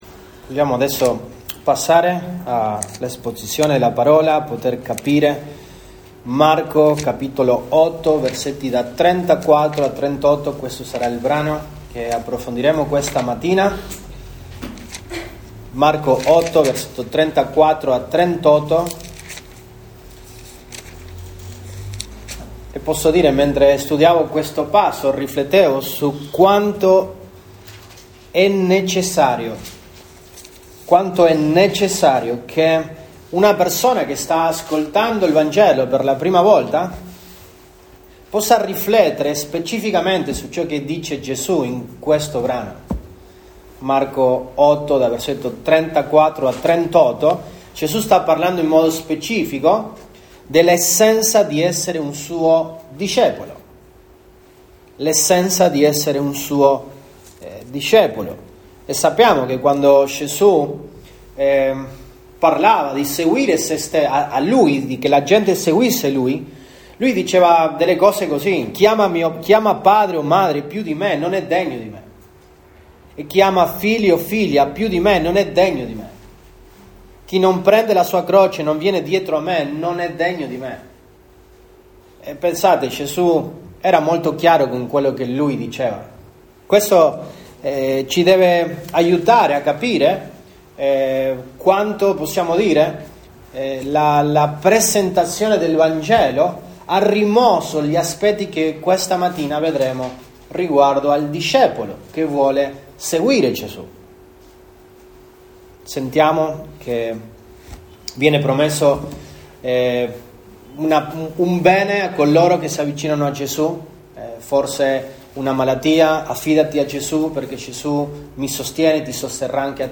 Dic 08, 2024 Gesù Cristo anuncia l’essenza di essere suo discepolo MP3 Note Sermoni in questa serie Gesù Cristo anuncia l’essenza di essere suo discepolo.